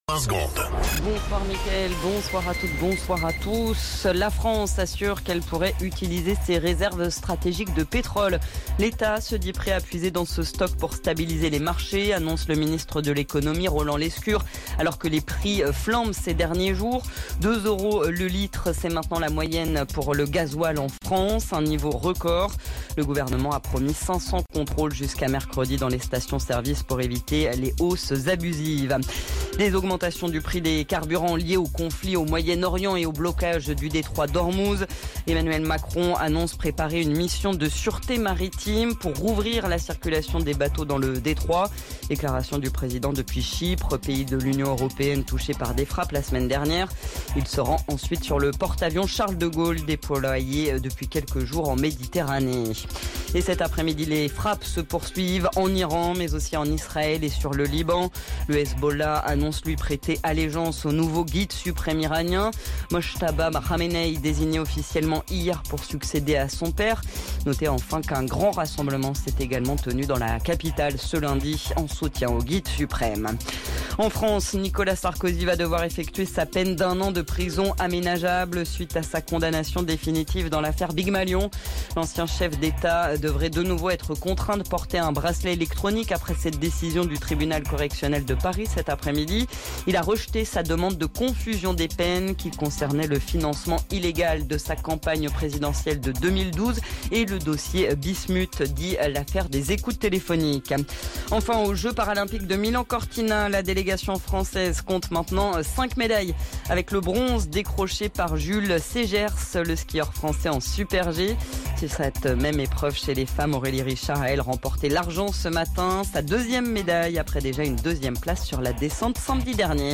Flash Info National 09 Mars 2026 Du 09/03/2026 à 17h10 .